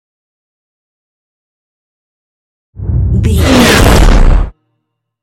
Scifi whoosh to hit
Sound Effects
futuristic
tension
woosh to hit